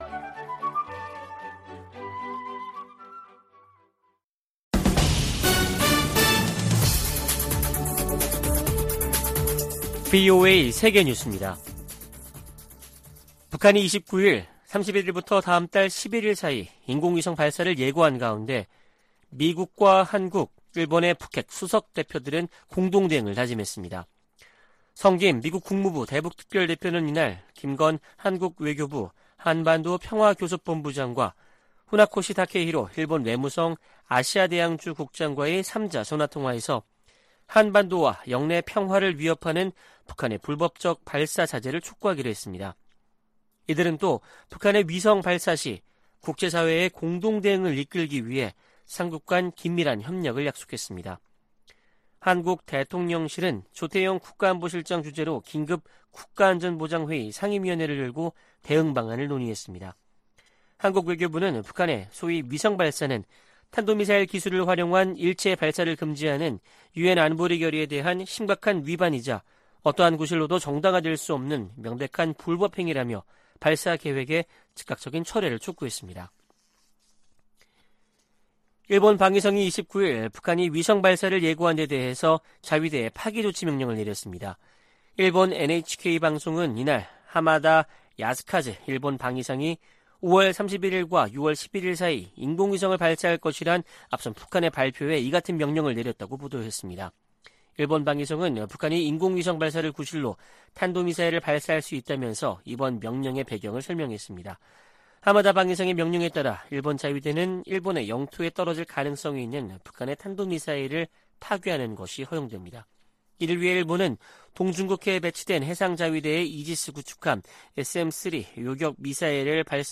VOA 한국어 아침 뉴스 프로그램 '워싱턴 뉴스 광장' 2023년 5월 30일 방송입니다. 북한이 군사정찰위성 1호기 발사 계획을 공개했습니다. 한국 정부는 발사를 철회하지 않으면 대가를 치를 것이라고 경고했습니다.